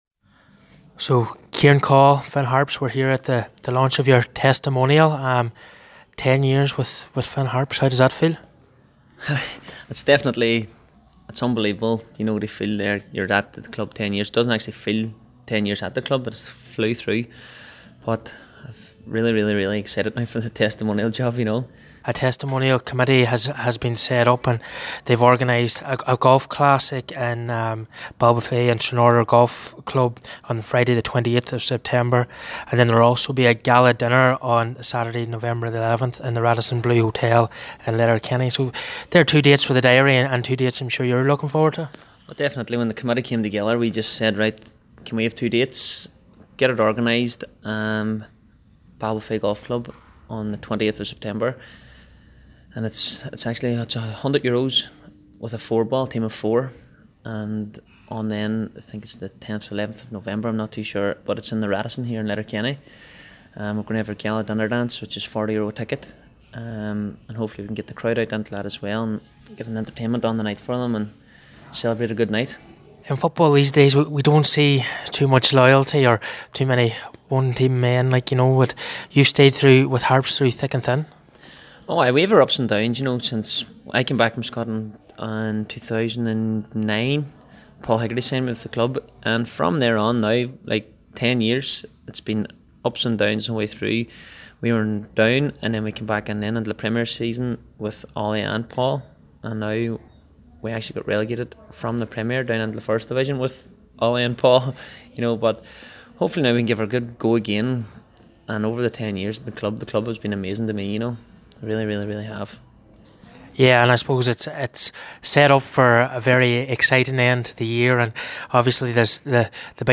at today’s announcement of his testimonial events…